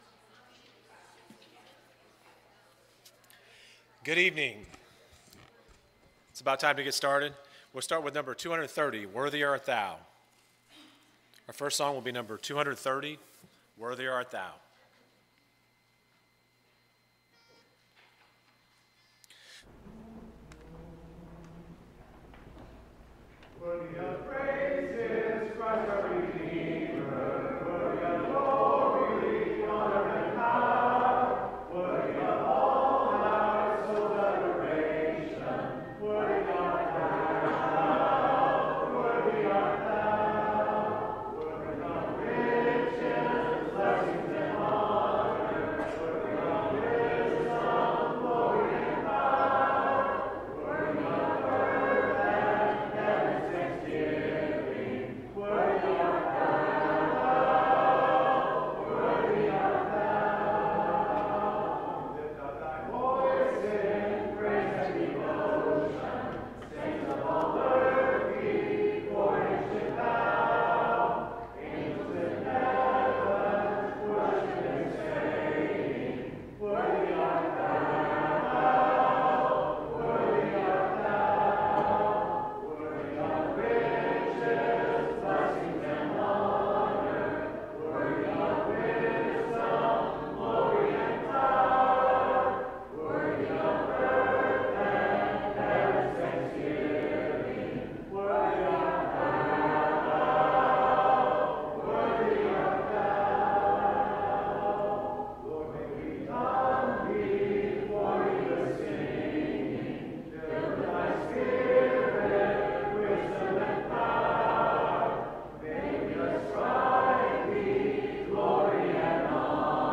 Romans 16:16, English Standard Version Series: Sunday PM Service